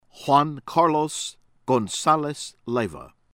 LAGE, CARLOS KAHR-lohs   LAH-hay